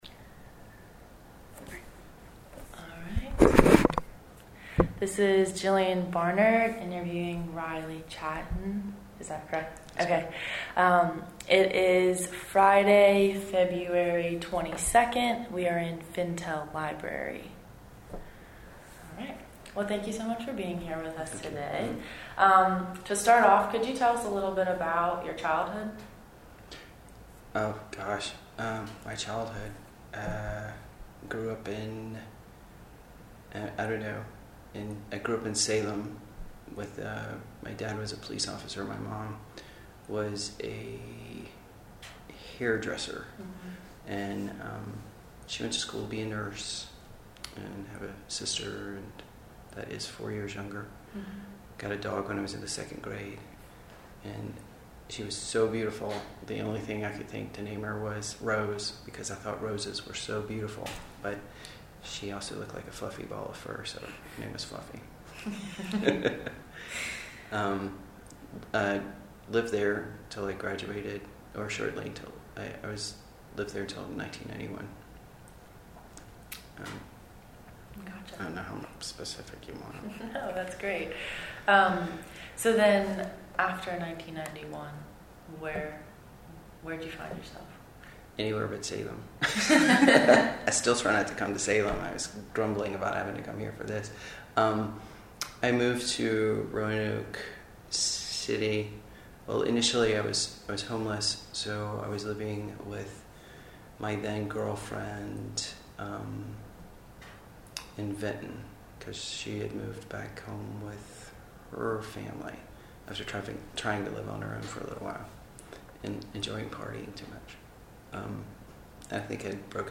Location: Fintel Library, Roanoke College